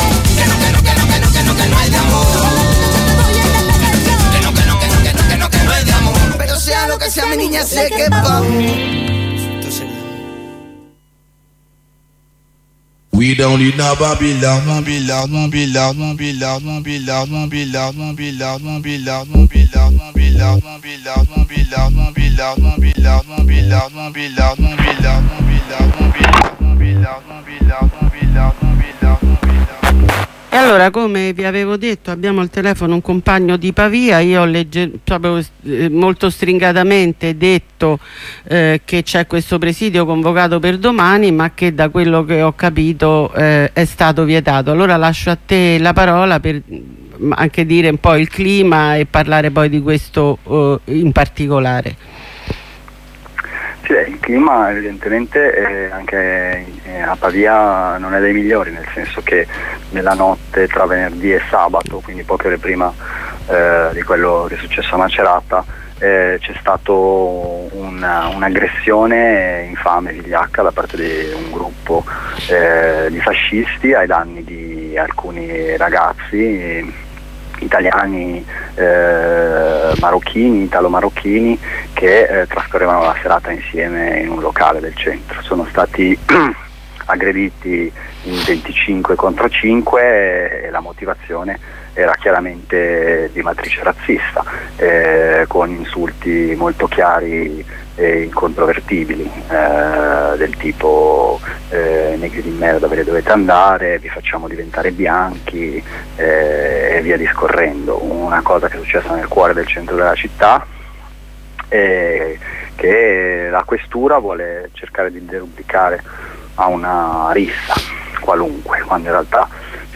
Corrispondenza con una maestra del Comitato Salacone Solidale